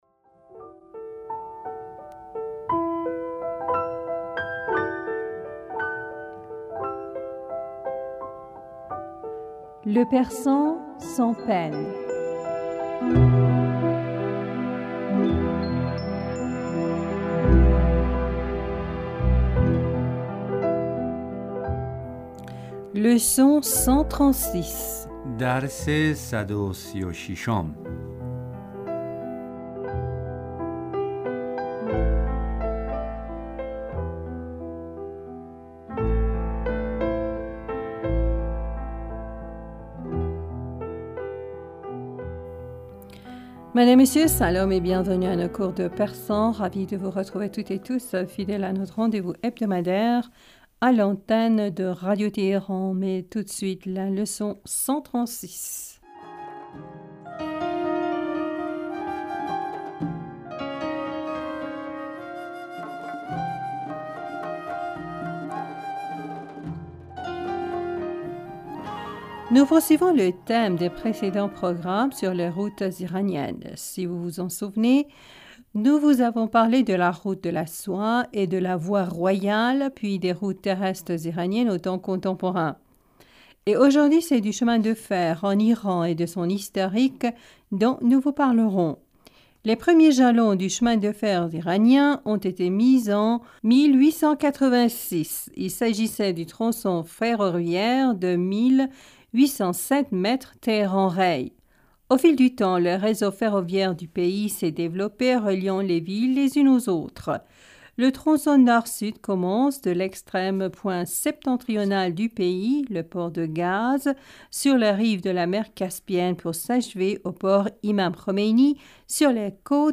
Madame, Monsieur, Salam et bienvenus à nos cours de persan.
Ecoutez et répétez après nous.